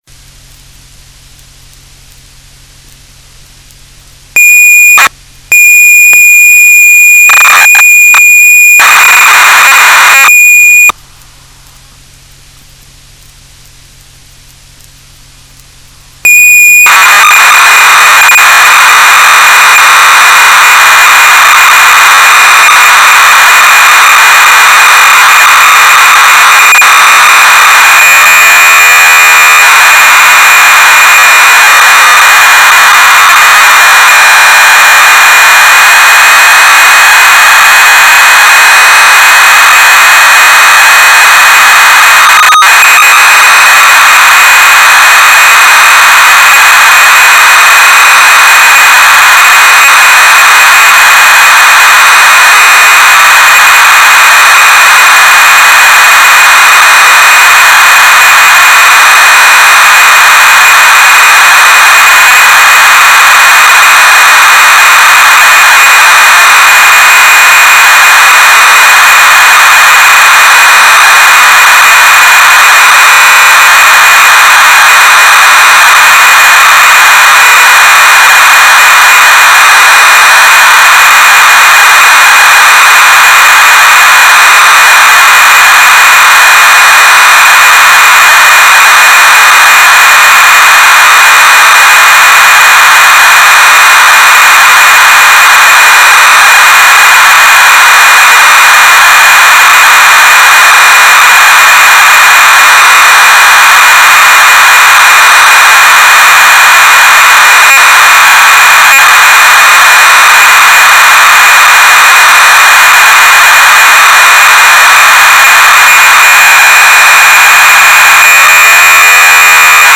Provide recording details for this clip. I have made recordings of the software tapes that I have owned using Audacity, a "free, open source, cross-platform software for recording and editing sounds".